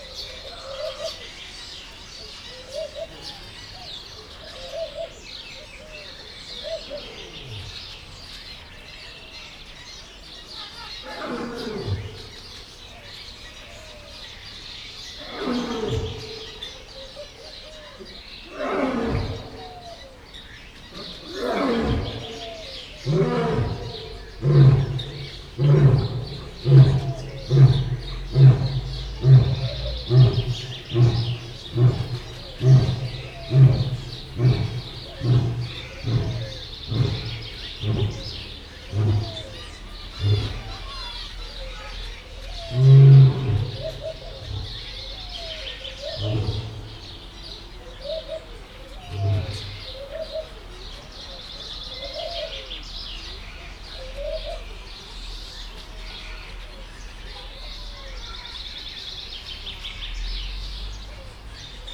himoroszlan_tavolrol01.02.wav